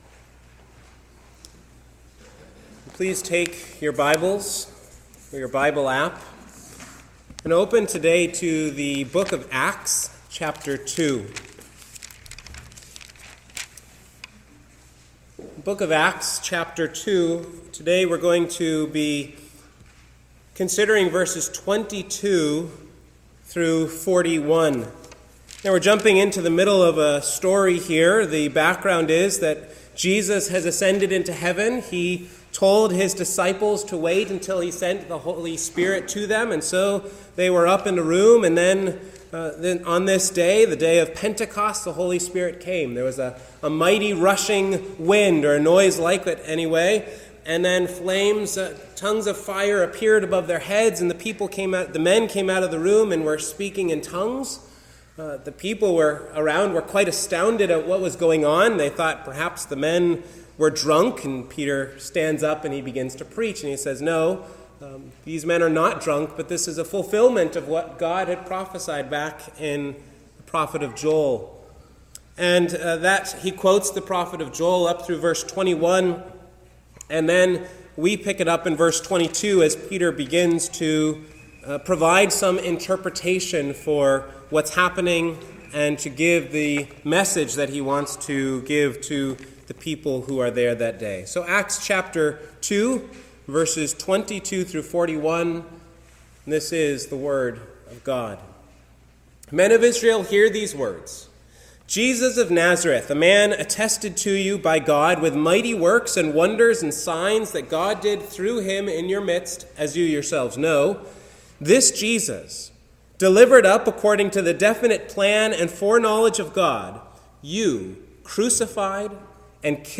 The Christian Message | SermonAudio Broadcaster is Live View the Live Stream Share this sermon Disabled by adblocker Copy URL Copied!